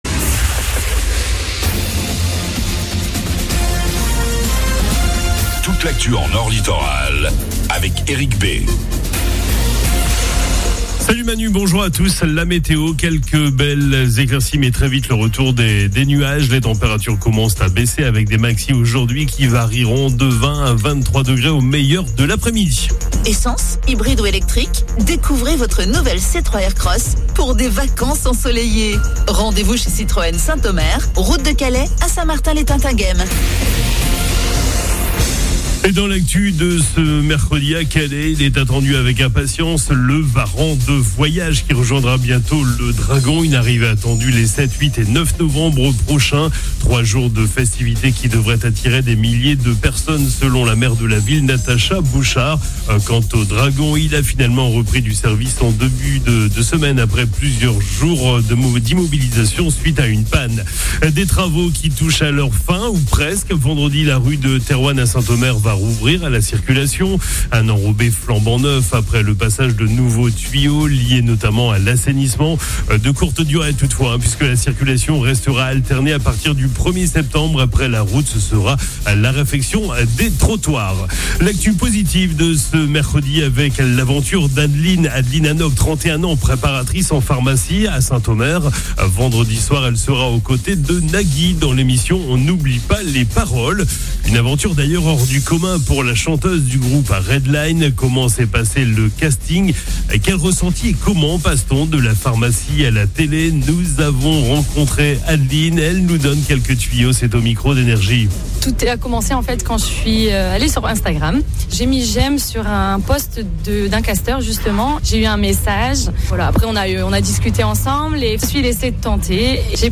FLASH 27 AOUT 25